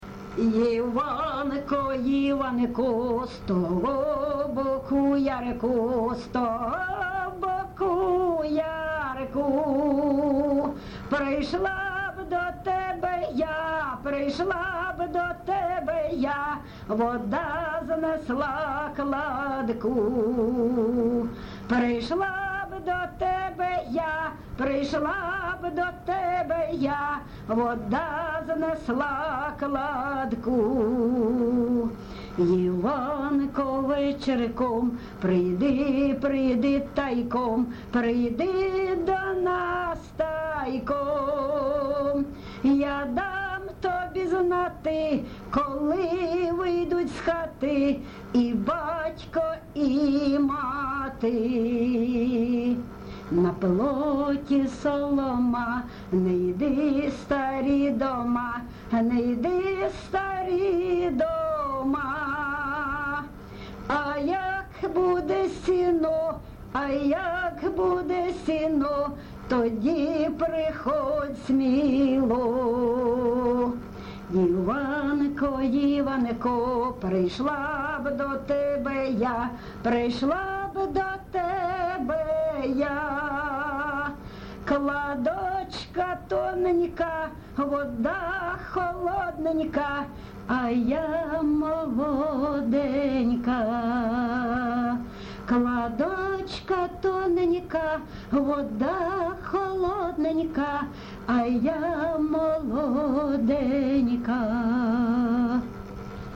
ЖанрПісні з особистого та родинного життя, Пісні літературного походження
Місце записус. Лозовівка, Старобільський район, Луганська обл., Україна, Слобожанщина